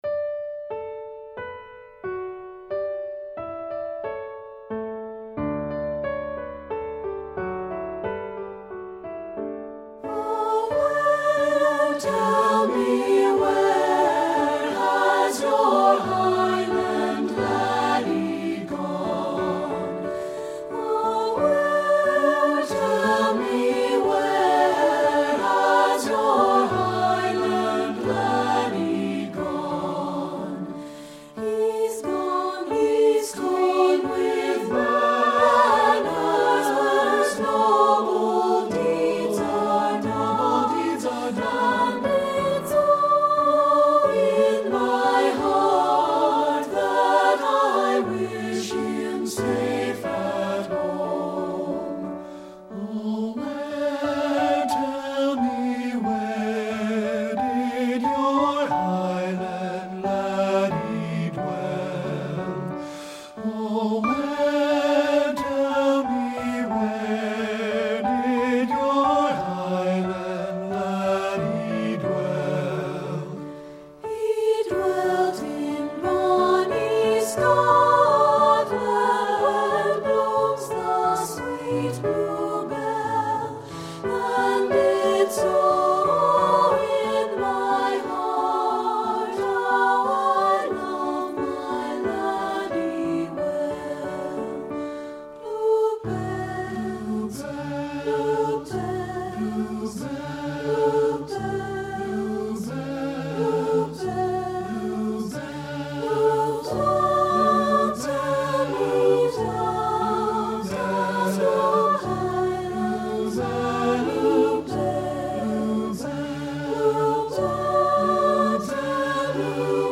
Composer: Scottish Folk Song
Voicing: 3-Part Mixed